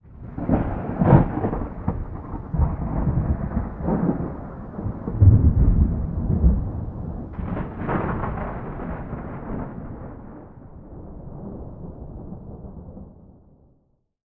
storm_1.ogg